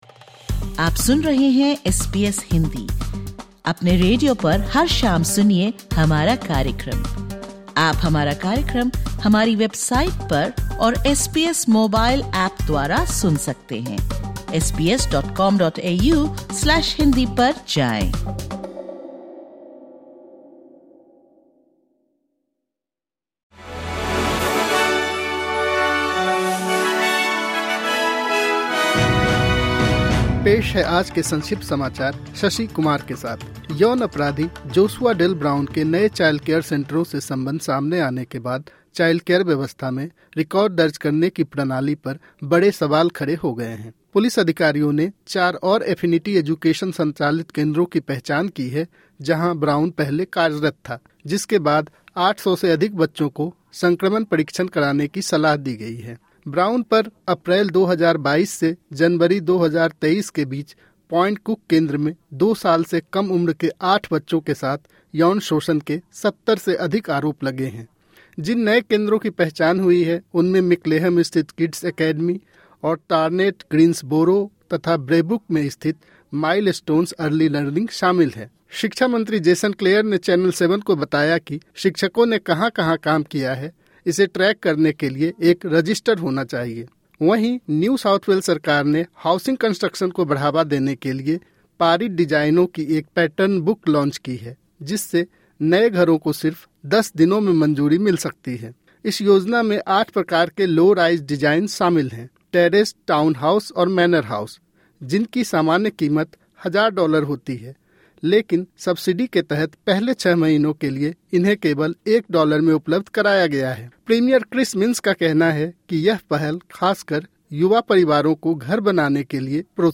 Top News: Childcare centres under review as sex offence charges widen in Melbourne